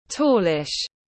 Khá cao tiếng anh gọi là tallish, phiên âm tiếng anh đọc là /ˈtɔː.lɪʃ/ .